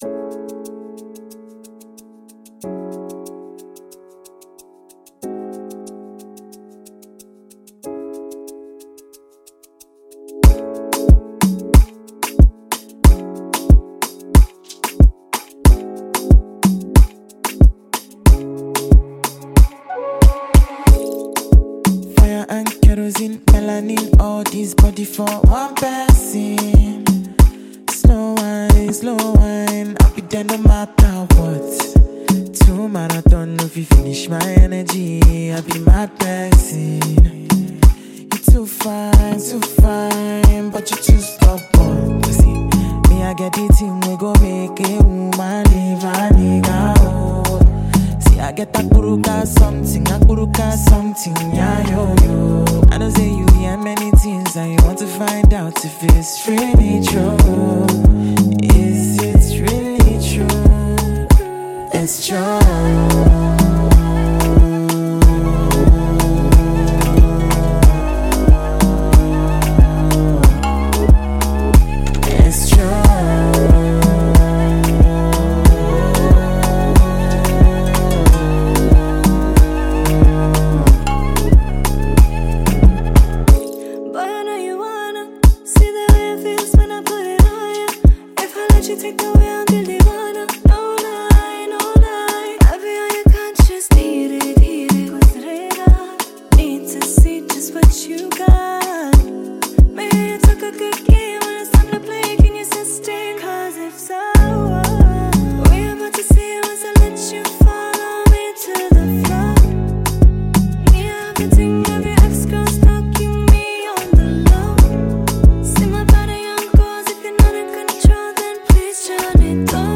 Nigerian Music